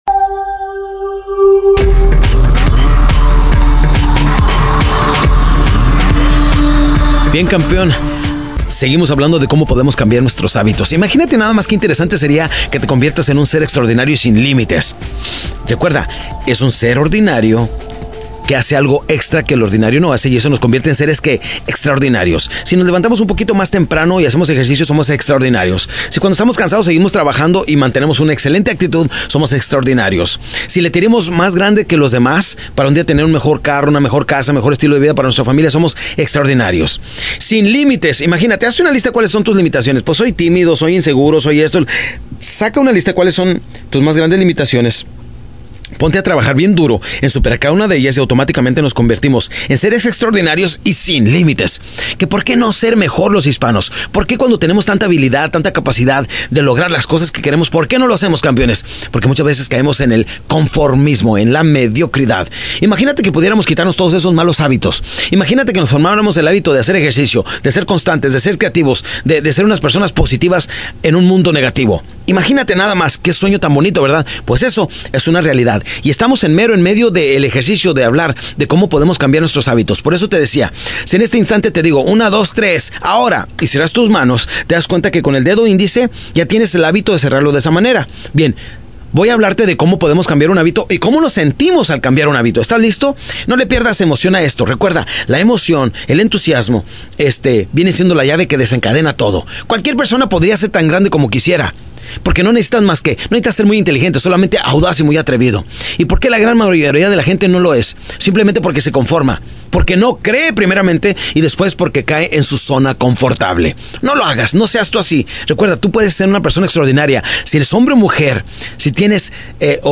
Audio Libros